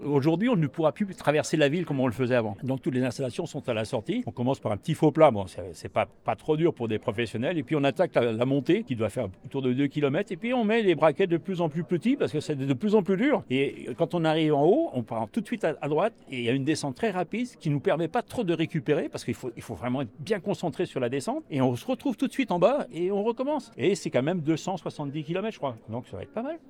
Les principaux acteurs de l’organisation, les élus ainsi que de nombreux athlètes se sont réunis, ce jeudi 23 avril 2026, au Congrès Impérial, à Annecy, pour présenter les contours de cet évènement spectaculaire. 14 sites ont été retenus et confirmés sur le département, avec le vélodrome de Saint-Quentin-en-Yvelines.